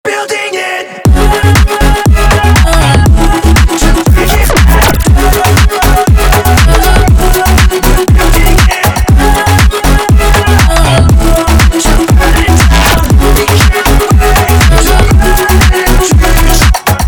I couldn't think of another placeholder acapella lol
Music / House
sketch demo house electronic edm